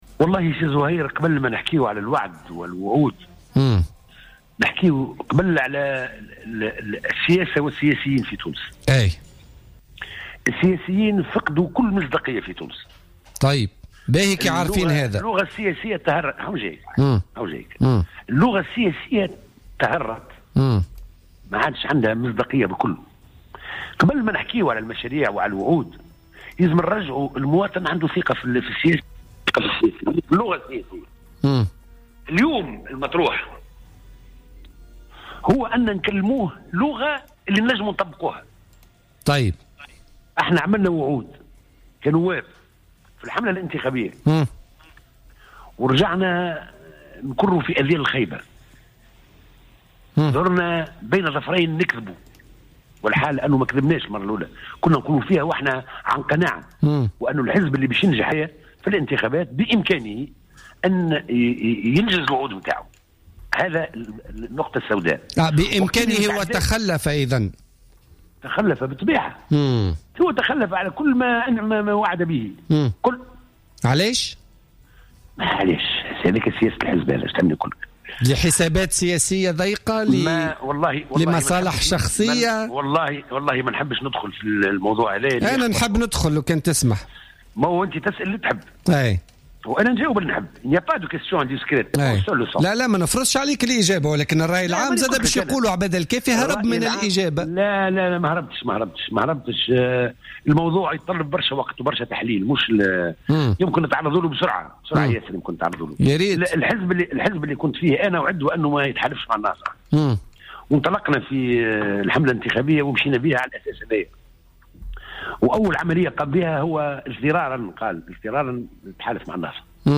قال عبادة الكافي النائب عن الكتلة الحرة وأحد مؤسسي حزب "حركة مشروع تونس" في تصريح للجوهرة أف أم في برنامج بوليتكا لليوم الخميس 17 مارس 2016 إنه على الدولة أن تكون قاطرة الاستثمار في المناطق المهمشة.